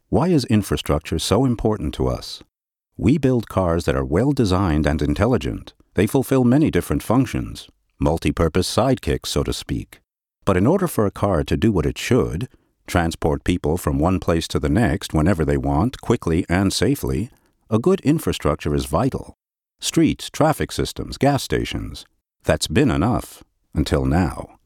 Native English Speaker; bass voice; technical, medical or documentary narration; excellent actor; short-notice access to studios
mid-atlantic
Sprechprobe: Industrie (Muttersprache):